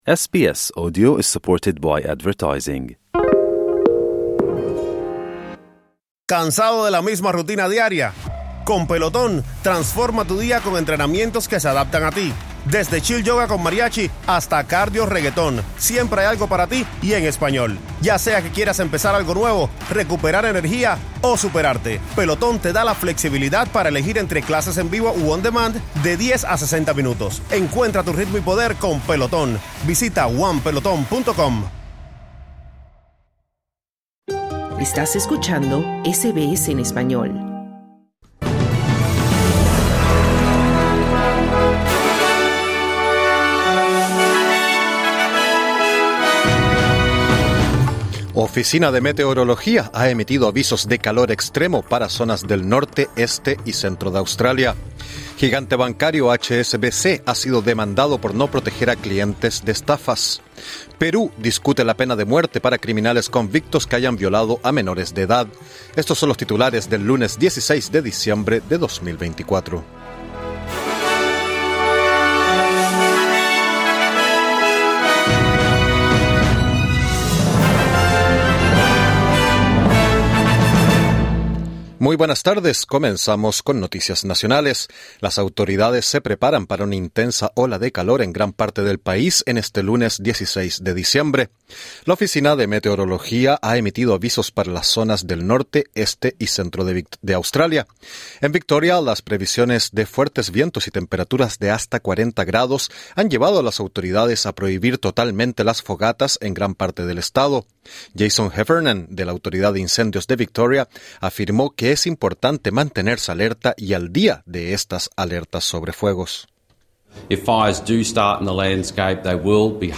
Noticias SBS Spanish | 16 diciembre 2024